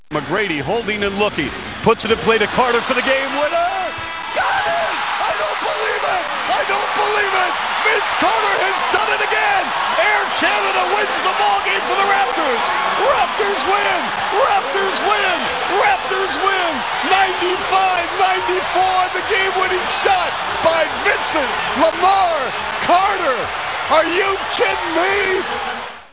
Bir sunucunun Carter smacini anlatisi